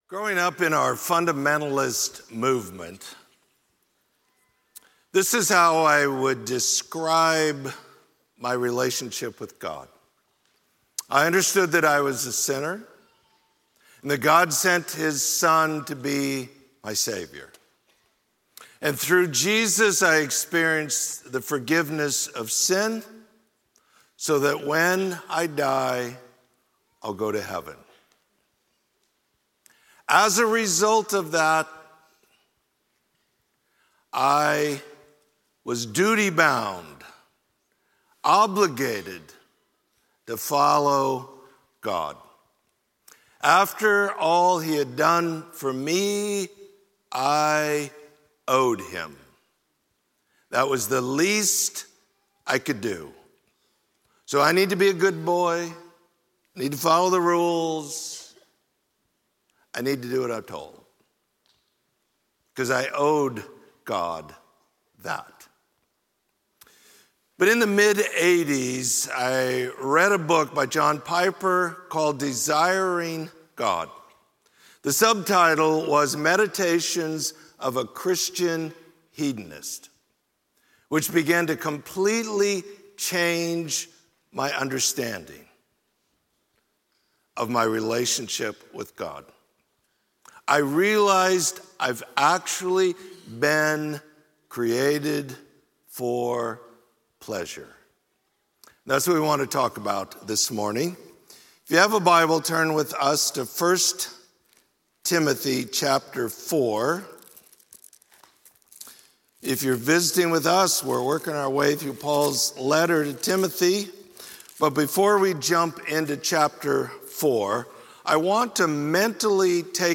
Sermon: Created for Pleasure